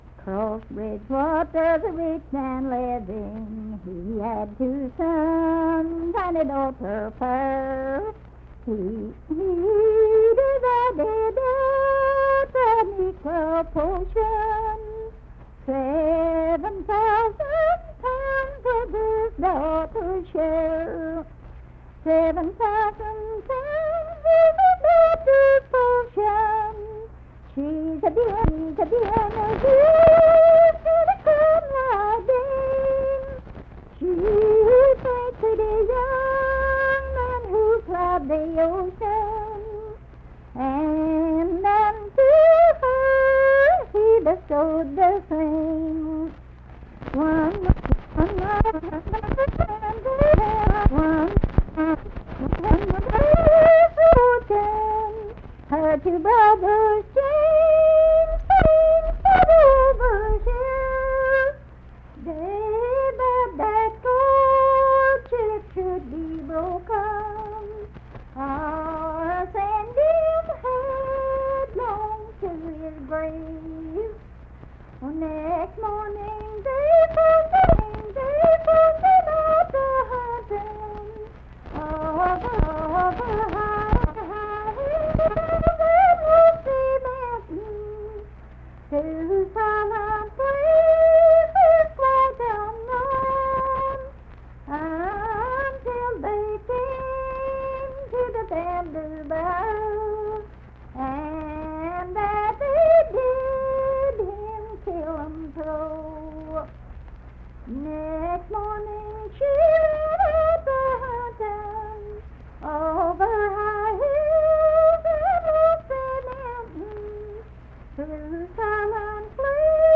Unaccompanied vocal music
Verse-refrain, 6(4-5).
Voice (sung)